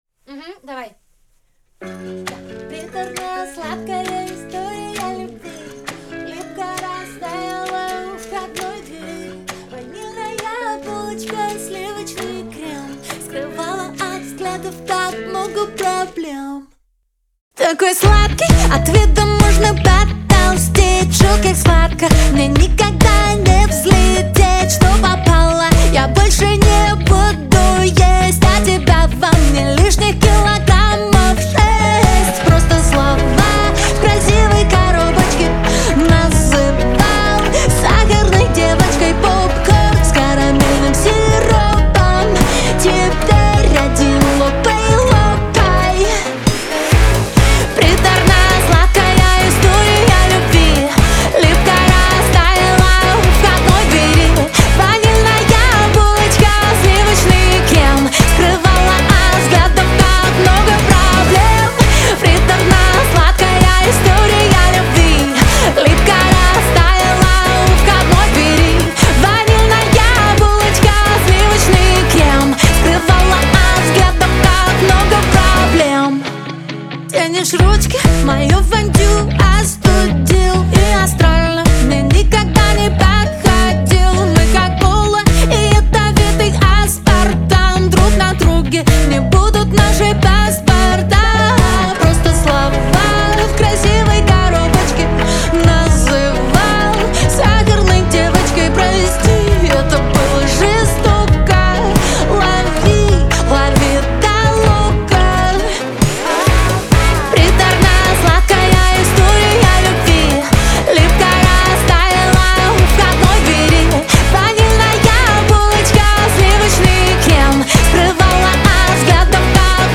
pop , Веселая музыка , эстрада , диско , Лирика